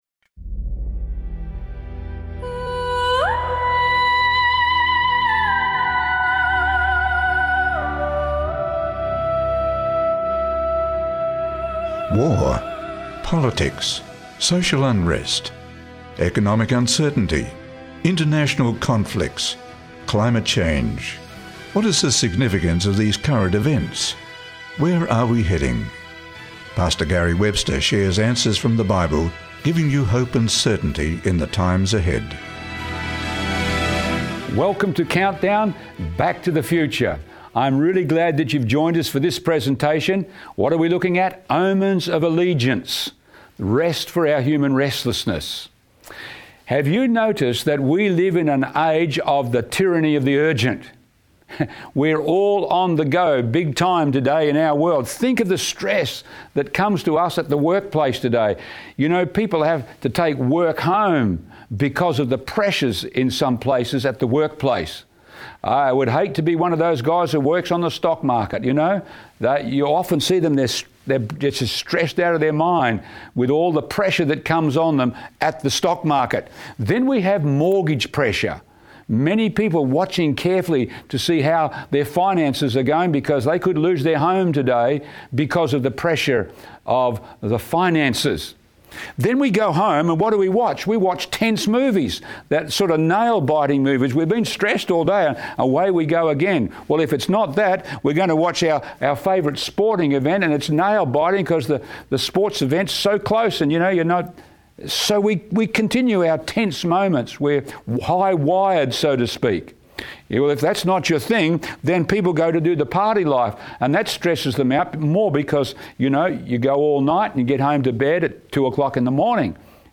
Discover the profound significance of God's end-time seal and the Sabbath rest in this captivating presentation. Explore the biblical truths behind the 144,000, the battle for global worship, and how the Sabbath holds the key to worshiping the Creator, experiencing redemption, and belonging to the Lord.